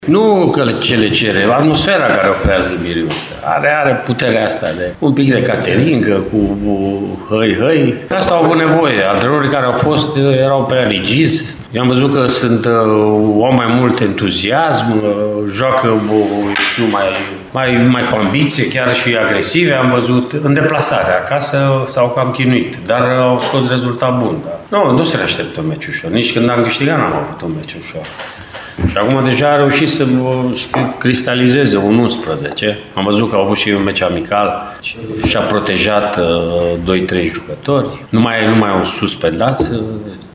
Antrenorul Mircea Rednic consideră extrem de important jocul din Copou și vorbește despre stilul impus de omologul său de la Iași, Vasile Miriuță: